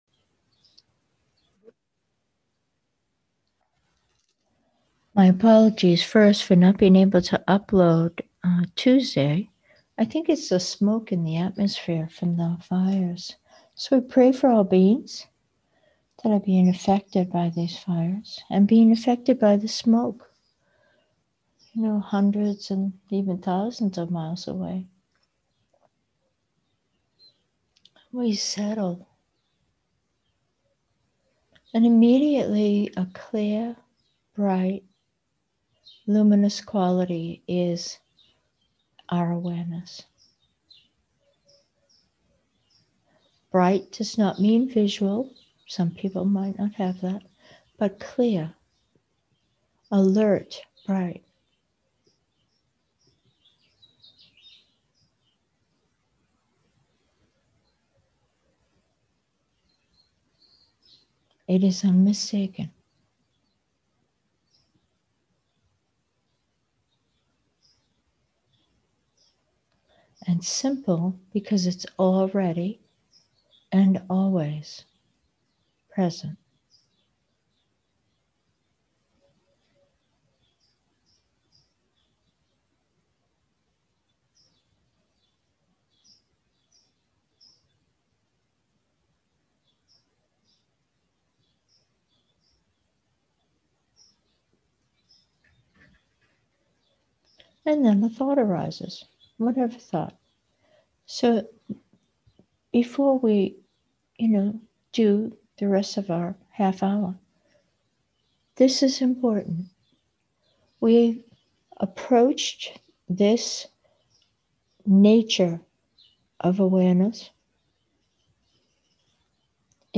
Meditation: luminous already